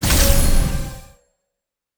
sfx_reward 02.wav